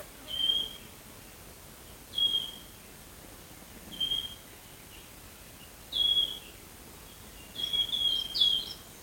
Wood Warbler, Phylloscopus sibilatrix
Ziņotāja saglabāts vietas nosaukumsKalnišķu Graviņas
StatusAgitated behaviour or anxiety calls from adults